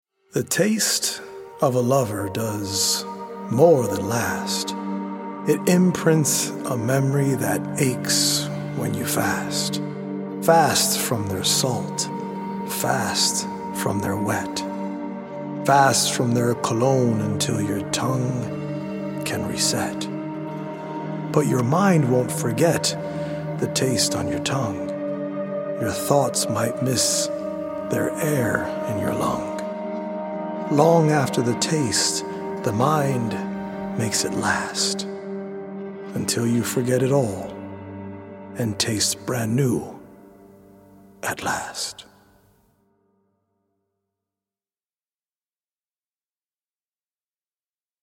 poetic recordings
healing Solfeggio frequency music